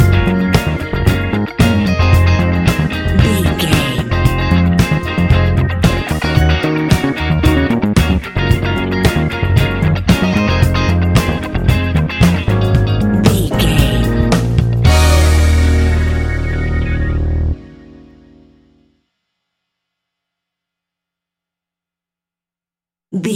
Ionian/Major
D♯
house
electro dance
synths
techno
trance
instrumentals